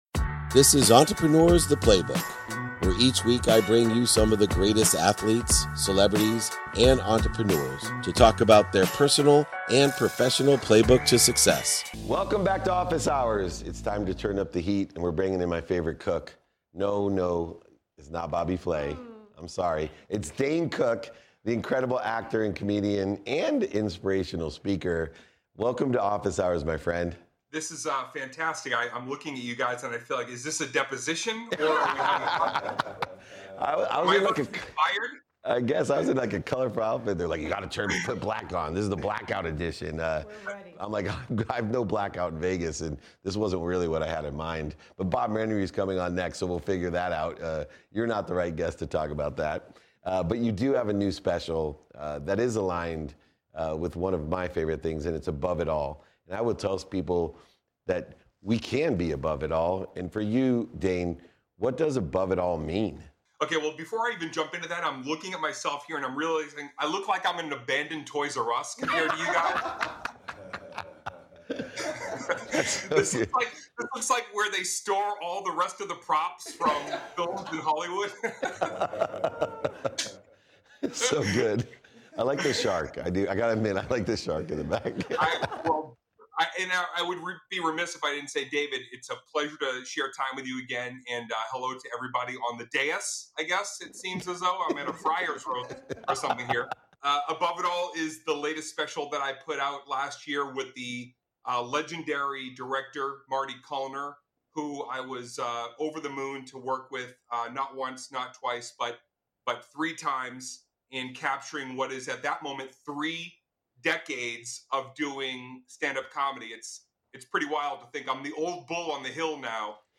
In our engaging conversation, Dane shares his journey from a shy, introverted kid to a stand-up comedy sensation. He reflects on the pivotal moments of his career, like the life-changing call from Lorne Michaels and hosting Saturday Night Live. Dane opens up about overcoming fear, embracing his dreams, and the importance of laughter as a healing gift.